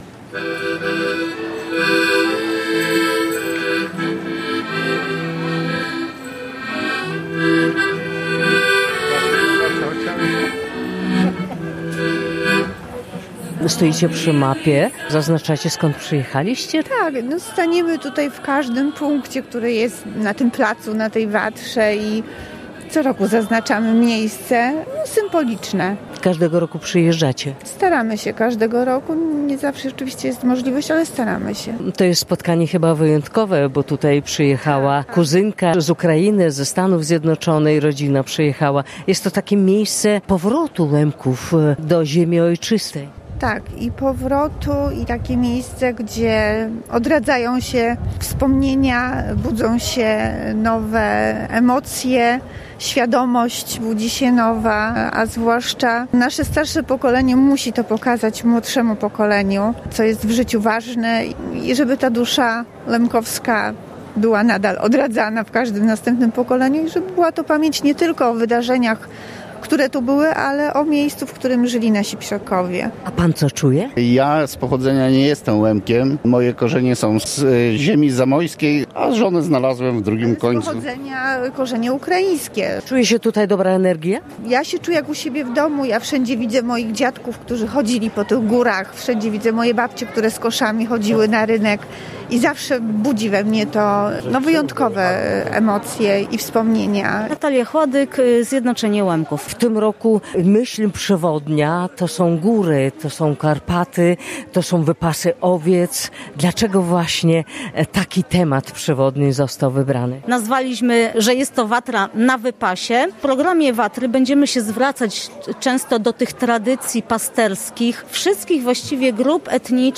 Łemkowie z całego świata przyjechali do Zdyni koło Gorlic na coroczny festiwal kultury łemkowskiej Watra.